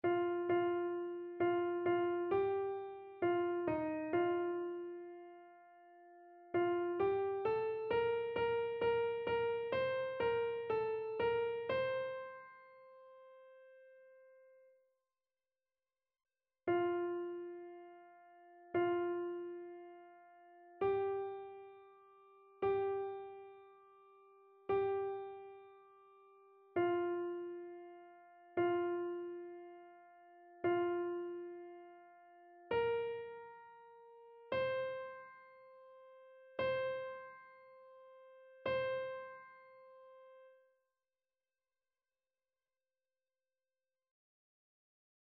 Soprano
annee-b-temps-ordinaire-6e-dimanche-psaume-31-soprano.mp3